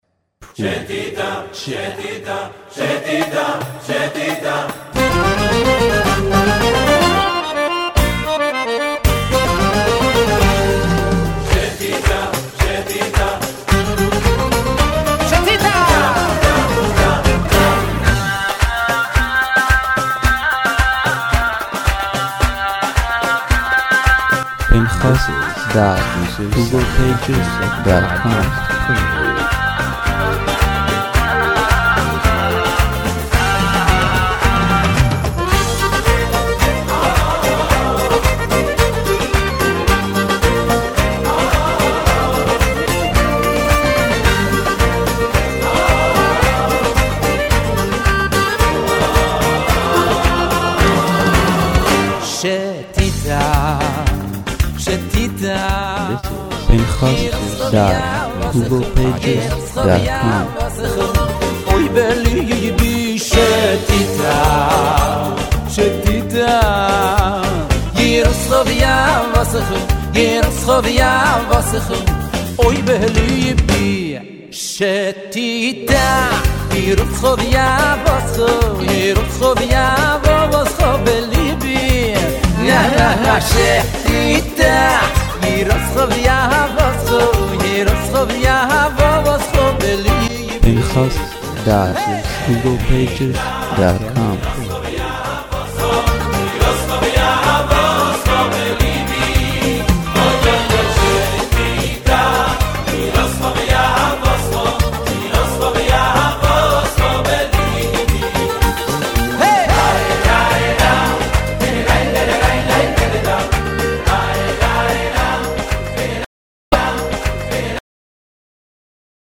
לאחר המתנה ארוכה של אלפי חובבי המוזיקה החסידית.